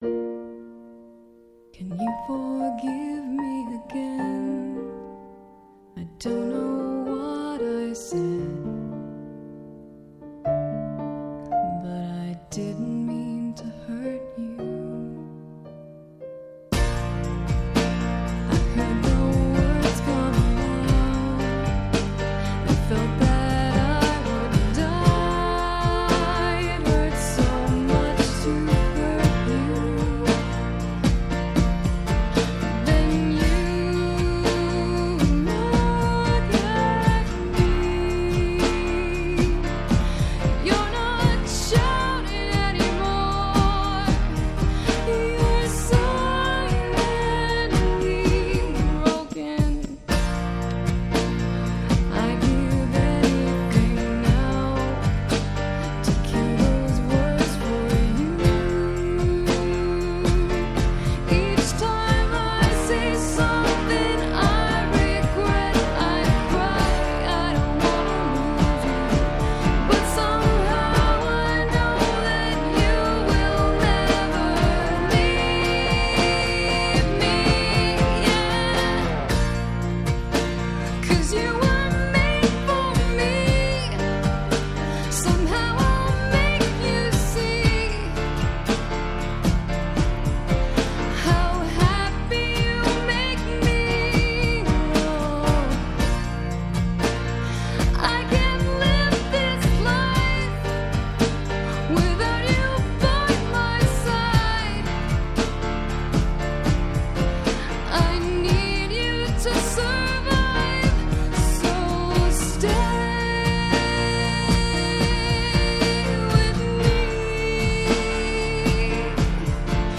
Жанр: Gothic Metal